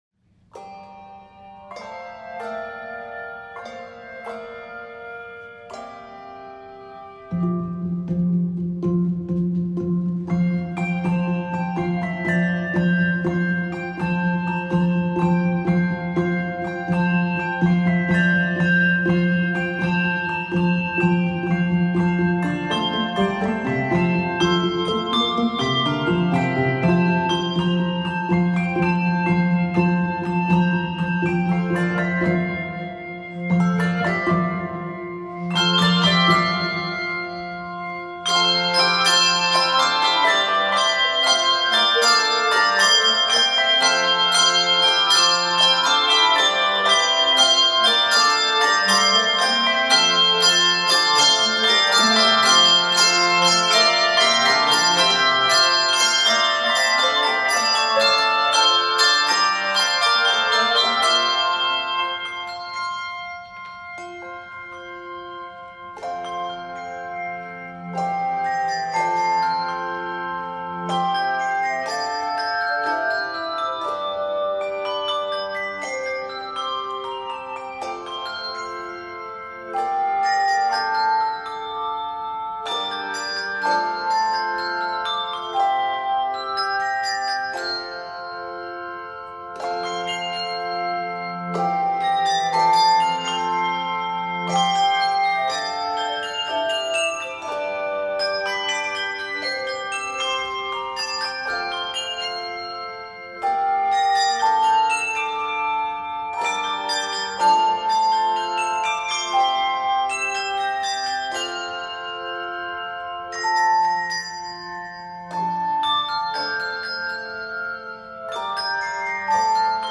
Scored in F Major, this setting is 119 measures.